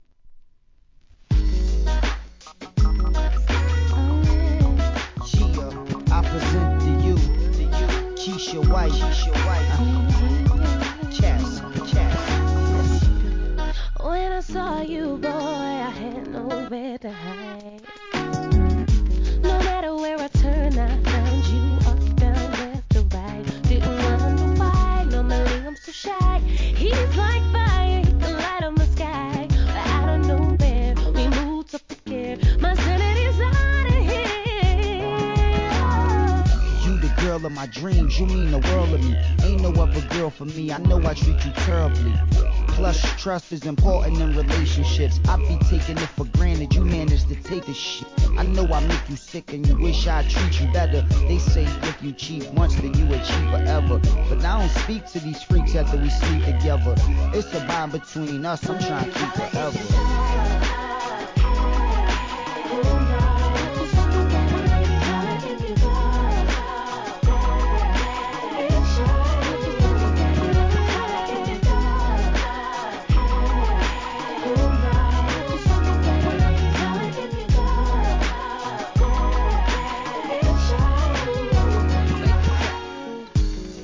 HIP HOP/R&B
アコースティックの美メロでしっとり歌い上げるR&B!!